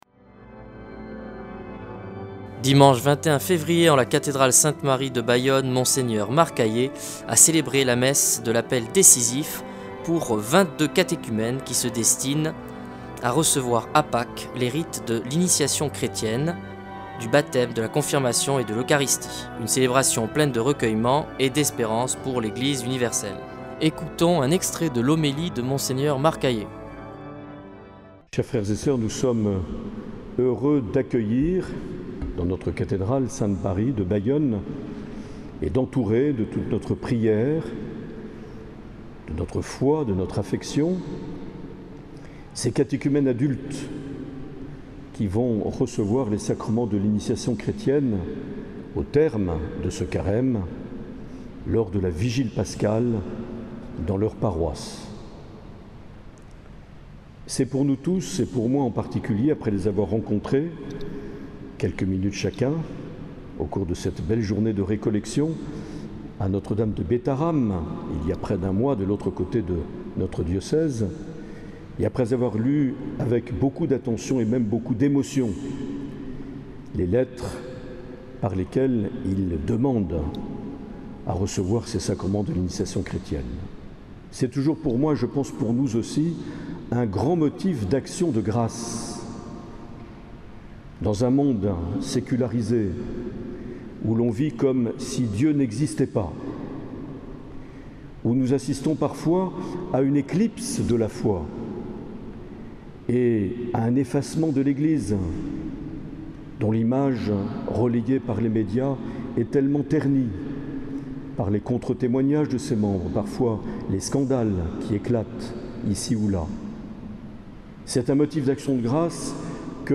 Mgr Aillet a procédé à l’appel décisif de 22 catéchumènes du diocèse le 21 février 2021 en la cathédrale de Bayonne.